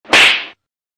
Slap Sound Effect